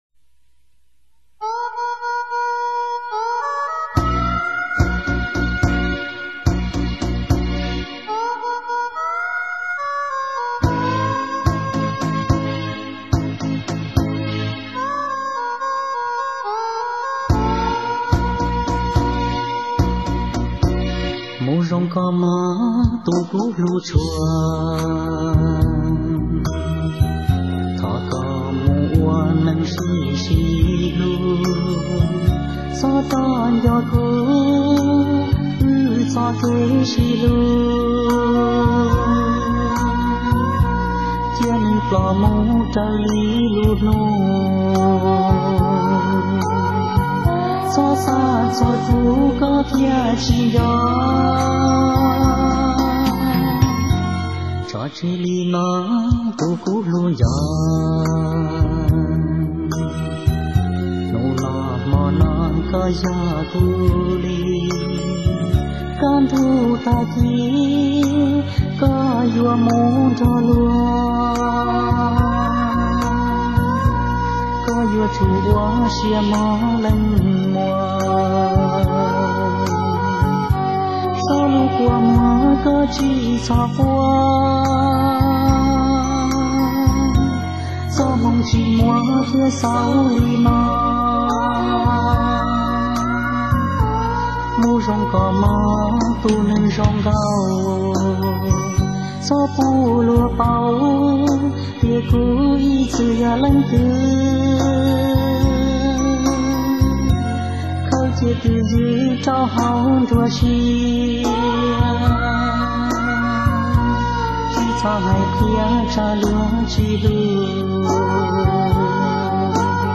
A very haunting and sad song.